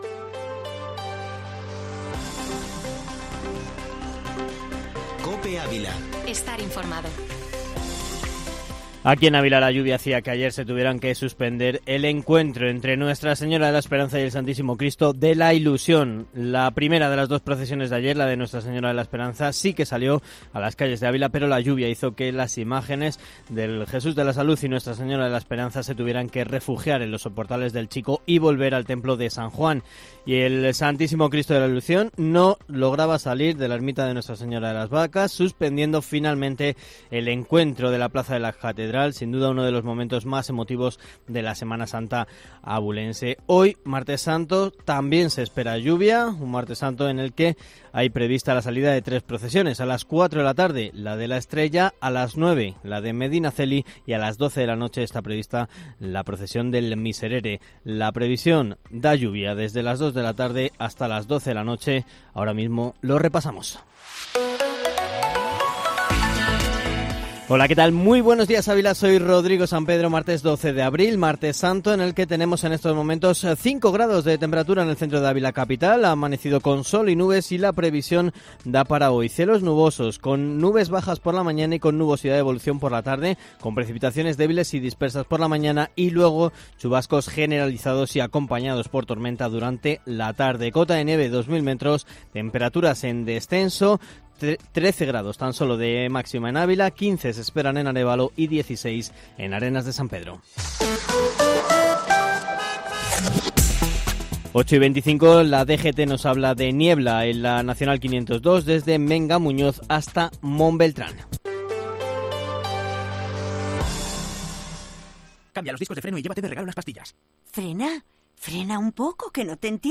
Informativo Matinal Herrera en COPE Ávila -12-abril